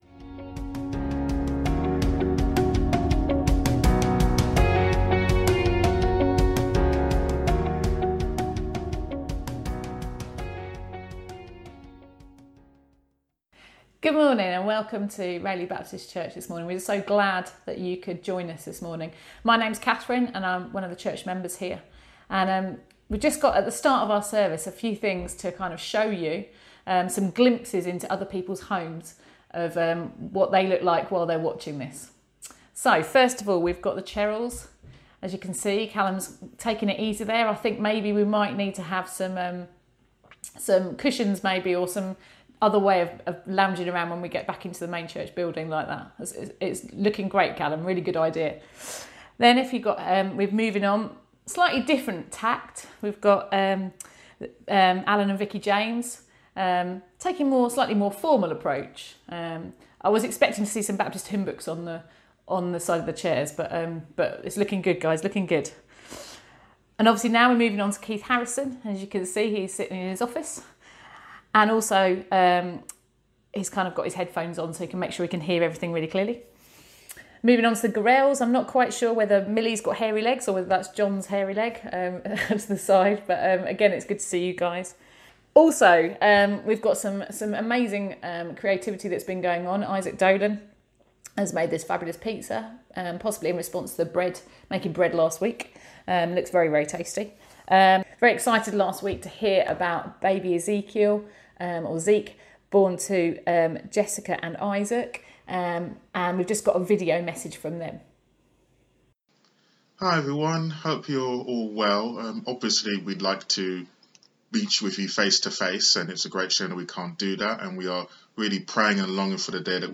A message from the series "Faith for Lifes Journey."